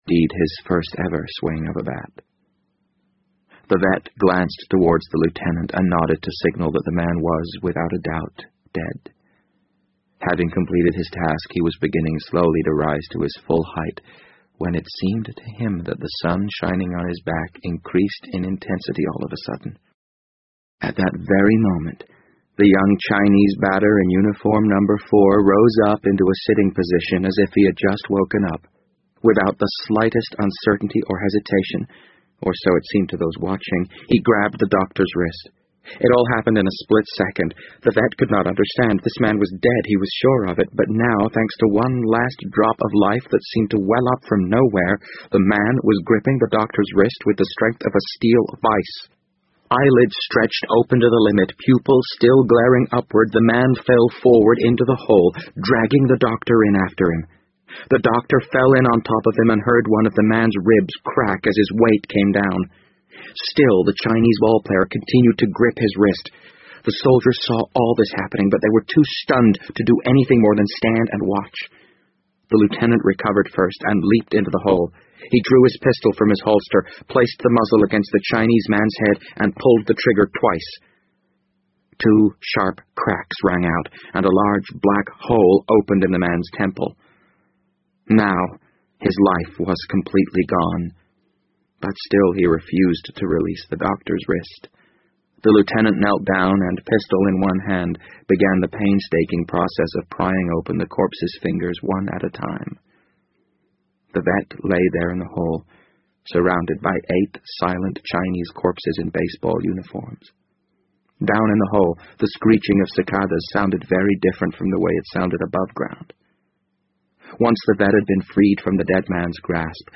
BBC英文广播剧在线听 The Wind Up Bird 013 - 12 听力文件下载—在线英语听力室